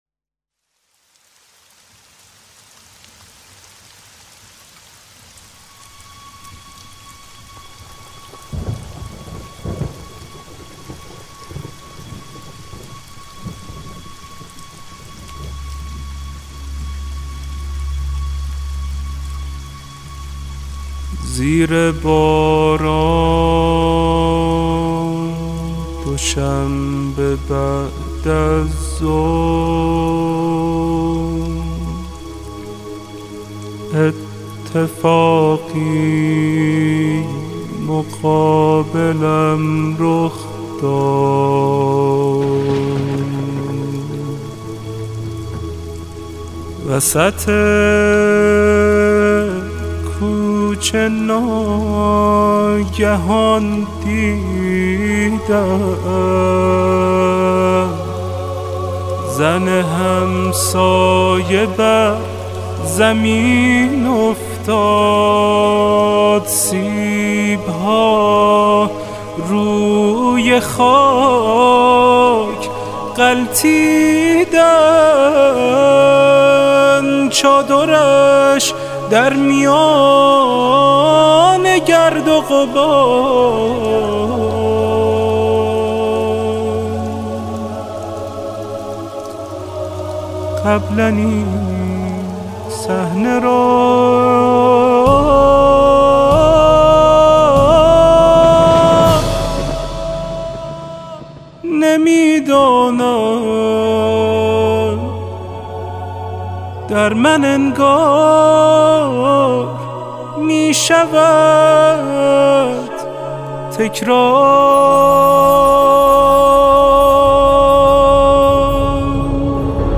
ویژه ایام فاطمیه